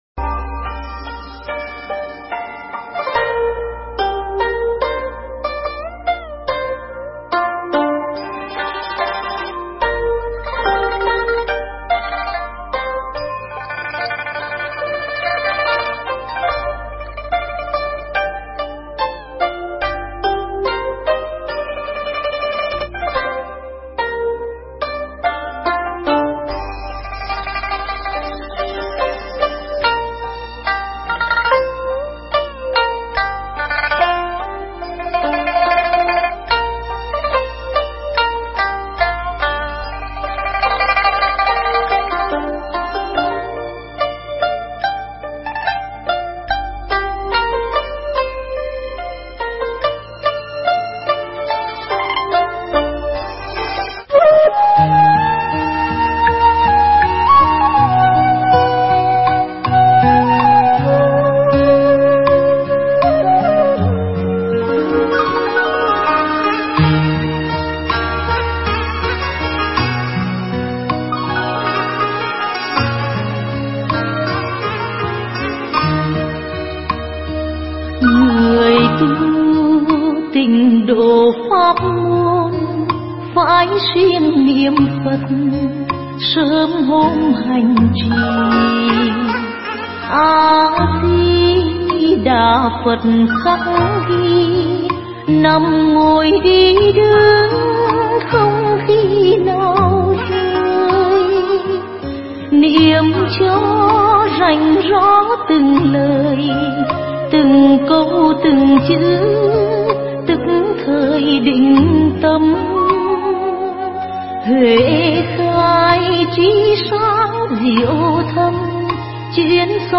Tải mp3 Thuyết Giảng Quyết Định Về Với Phật
giảng tại chùa Phước Long